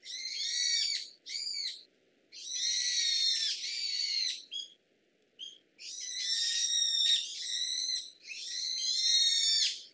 ヘルプ 詳細情報 ヒヨドリ 大きさ スズメより大きい 季節 春夏秋冬 色 灰色 特徴 一般に野鳥は、種類によって大きさや鳴き声が異なりますが、さらに観察すると、飛び方にもさまざまなバリエーションがあることに気が付きます。